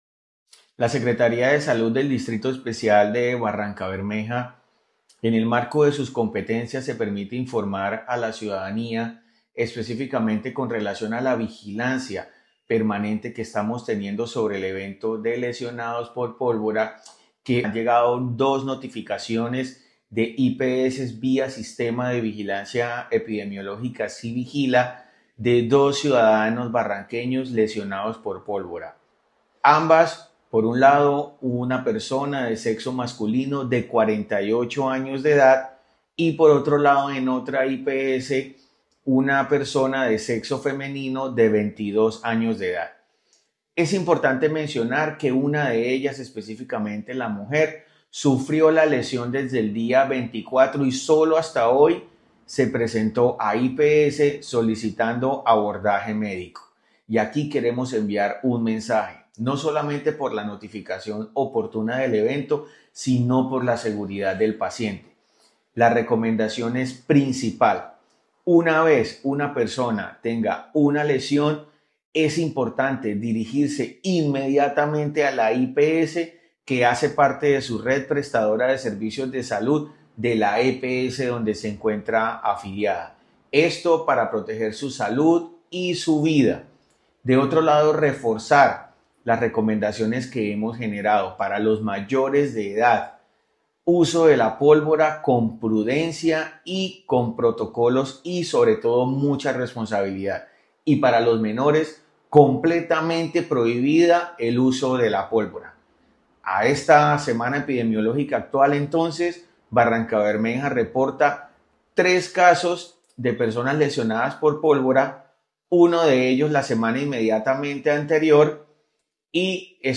El secretario de Salud distrital, Andrés Manosalva